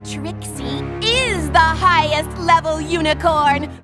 Worms speechbanks
BRILLIANT.WAV